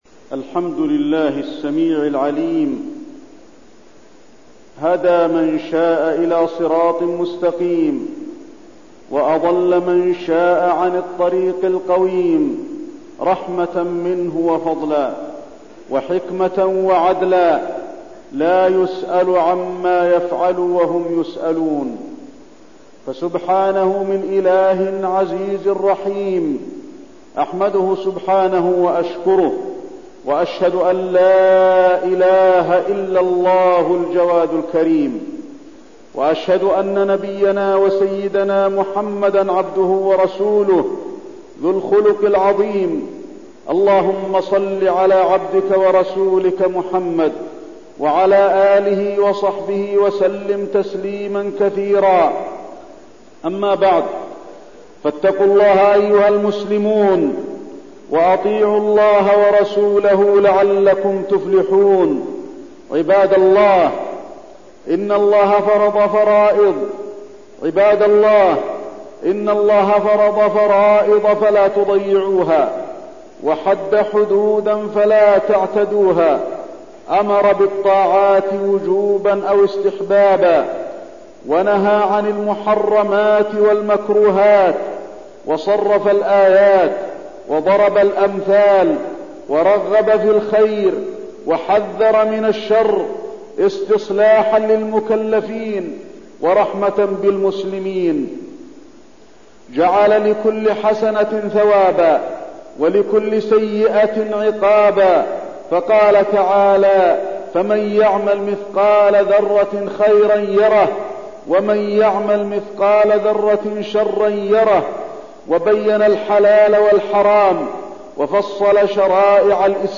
تاريخ النشر ١٢ شوال ١٤١١ هـ المكان: المسجد النبوي الشيخ: فضيلة الشيخ د. علي بن عبدالرحمن الحذيفي فضيلة الشيخ د. علي بن عبدالرحمن الحذيفي العبادة وفضلها